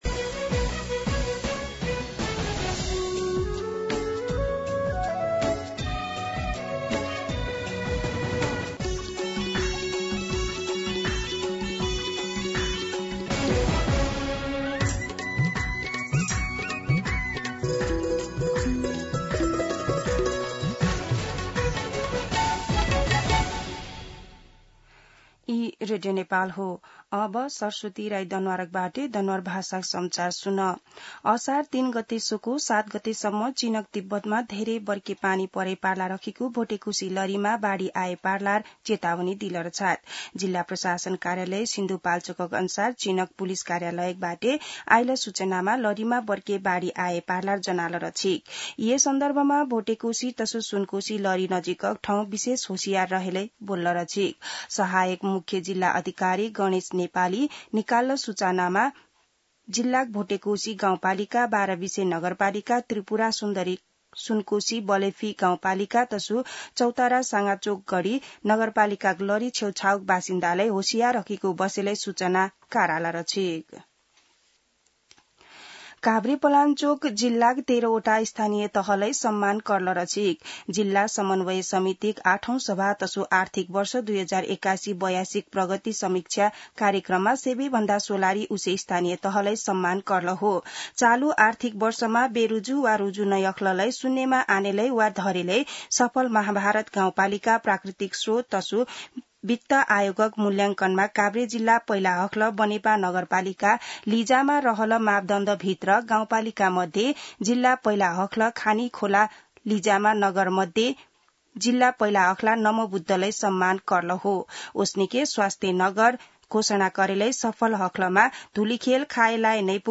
दनुवार भाषामा समाचार : ४ असार , २०८२